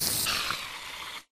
mob / spiderdeath / death.ogg
death.ogg